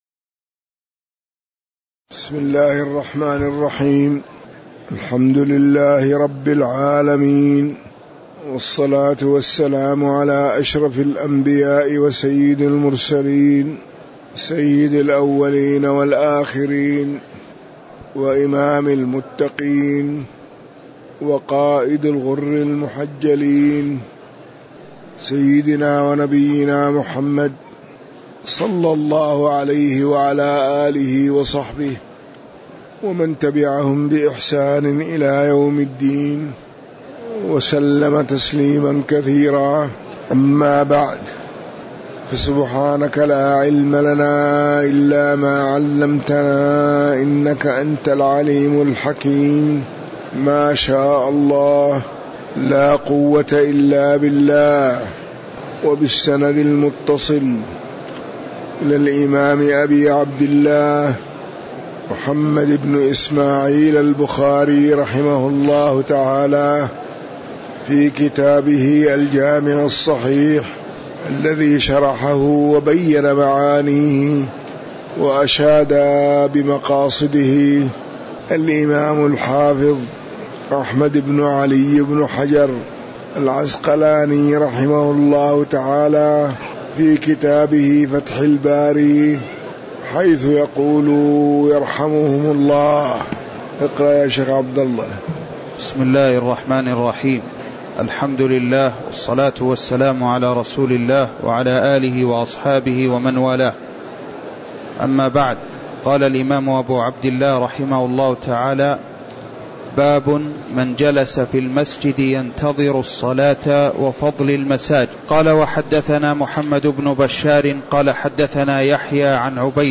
تاريخ النشر ١٣ جمادى الأولى ١٤٤١ هـ المكان: المسجد النبوي الشيخ